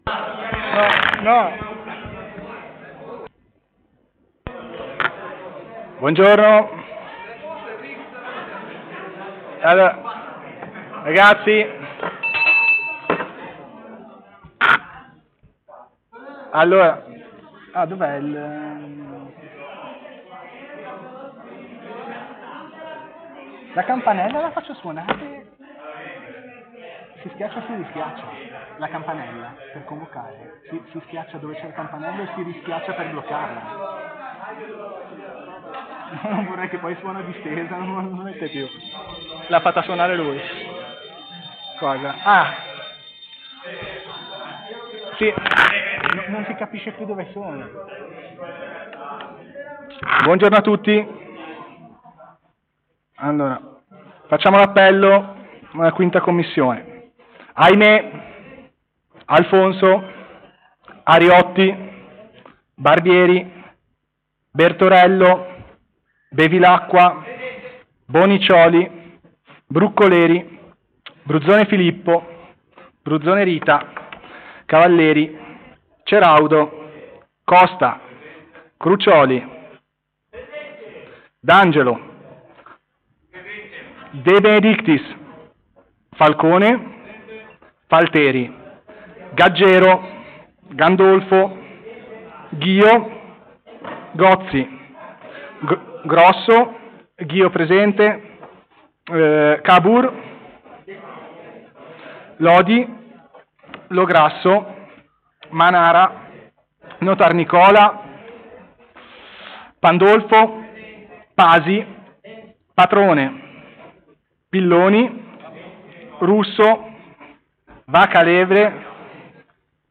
Luogo: Presso la Sala Consiliare di palazzo Tursi-Albini
Audio seduta: commissione_consiliare_v_di_lunedi_09_settembre_2024_ore_1430.mp3